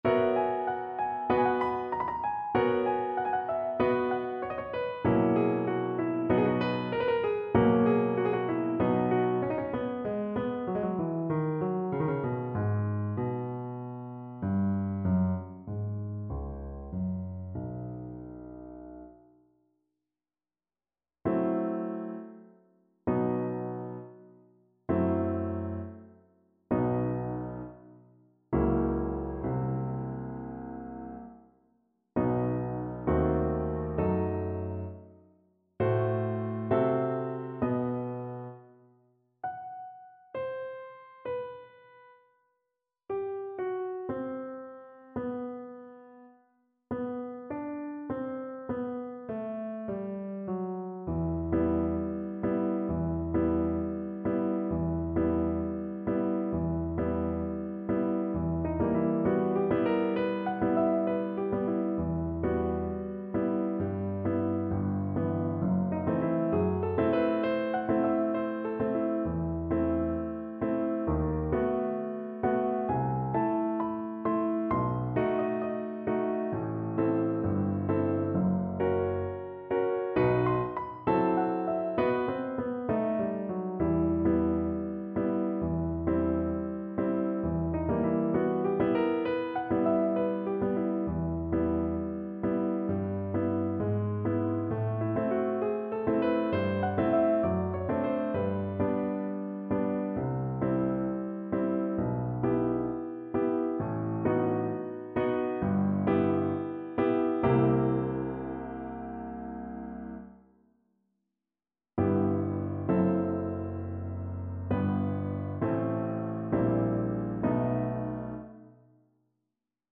4/4 (View more 4/4 Music)
Moderato =96
Classical (View more Classical Tenor Voice Music)